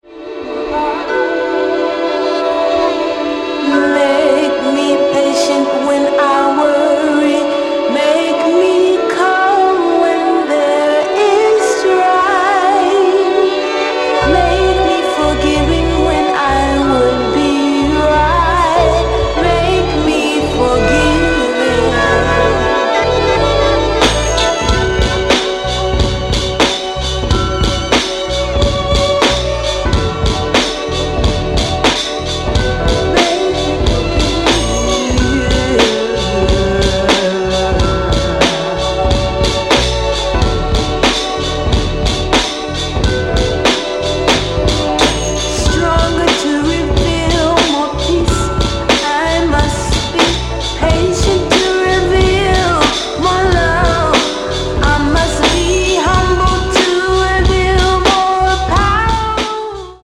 Gu Zheng